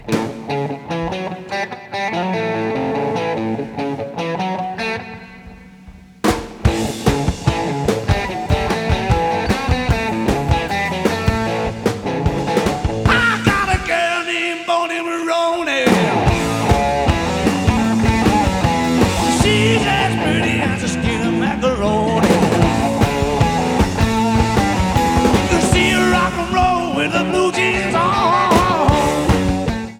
Sound Samples (All Tracks In Stereo)